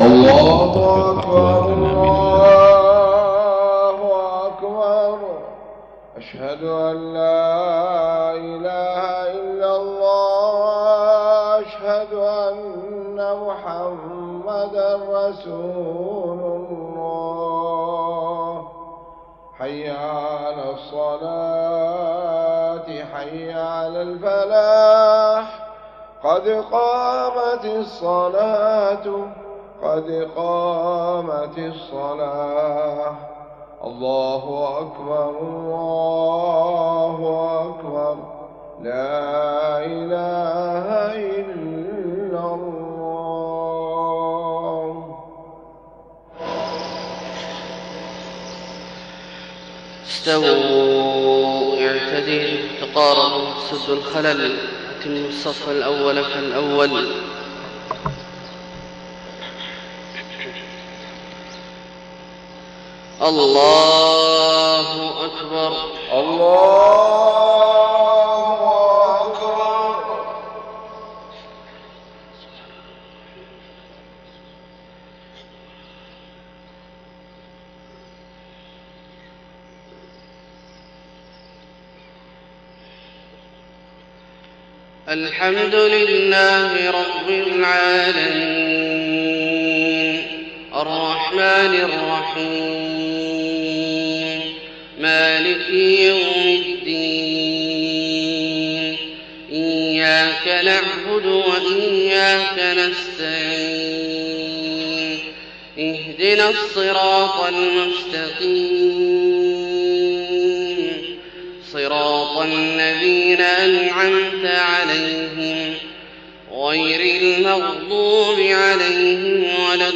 صلاة الفجر 12 صفر 1430هـ من سورة الكهف 32-49 > 1430 🕋 > الفروض - تلاوات الحرمين